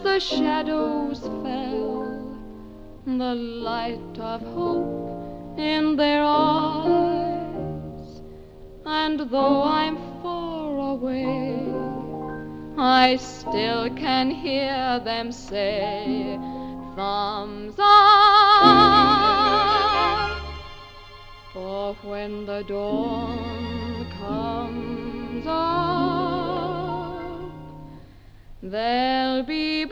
Genre: Vocal Pop